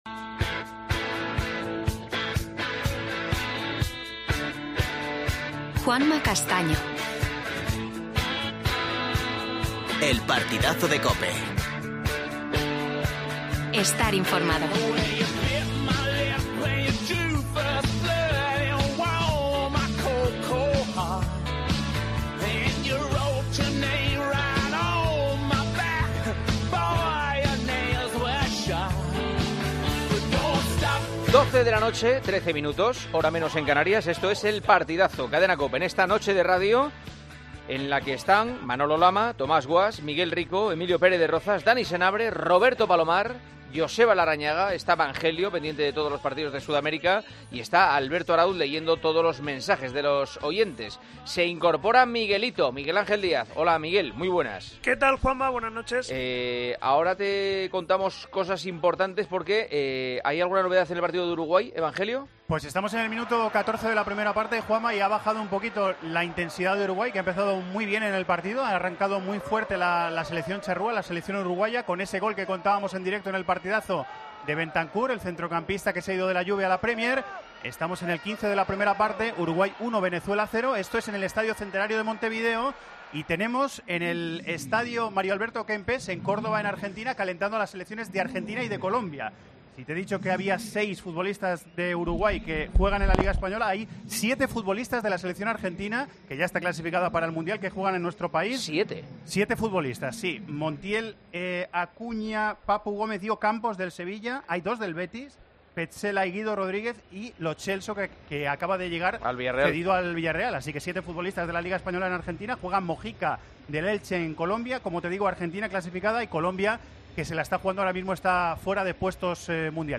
AUDIO: Actualidad del Real Madrid marcada por los partidos de selecciones en Sudamérica. Entrevista a Julio Baptista.